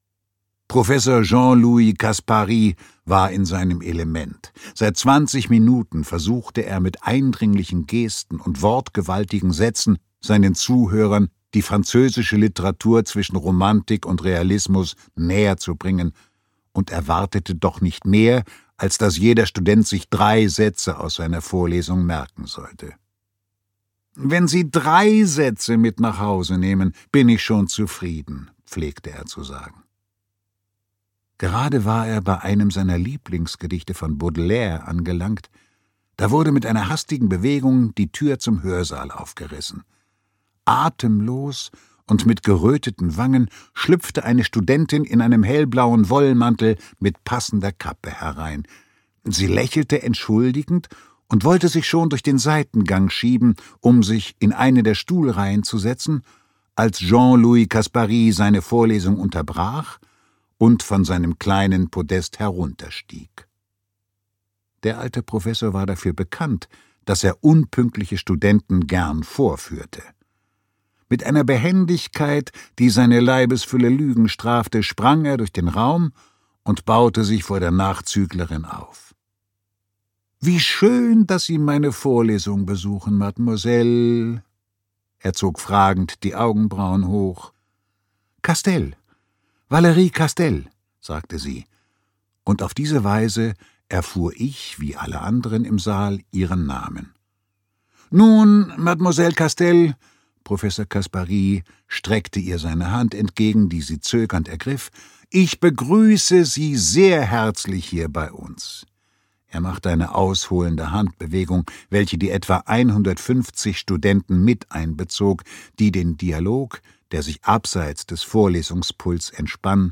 Menu d'amour - Nicolas Barreau - E-Book + Hörbuch